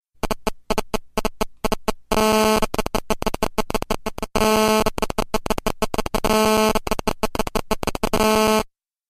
signal-interference_24962.mp3